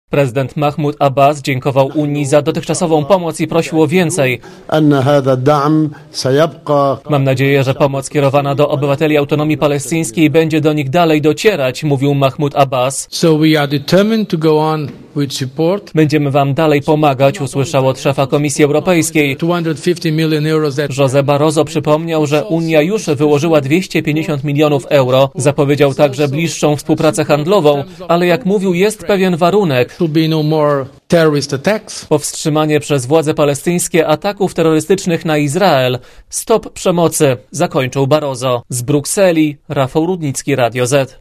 Korespondencja z Brukseli